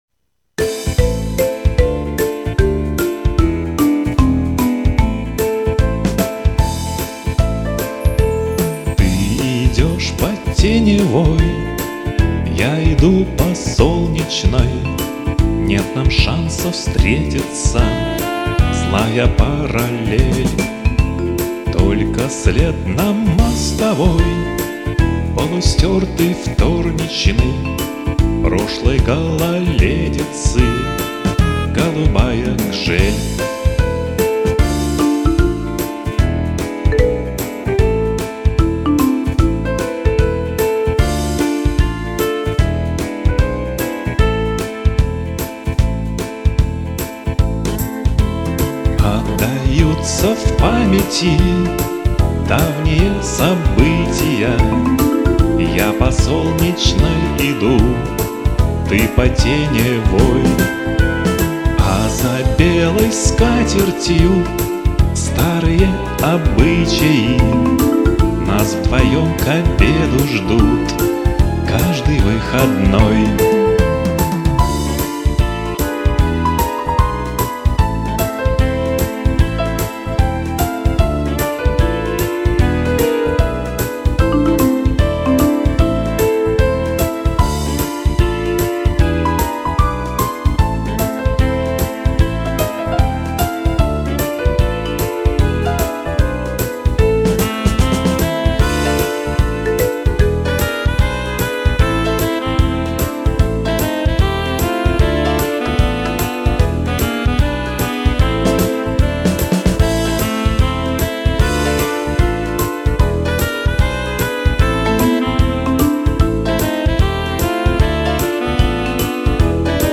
лирическая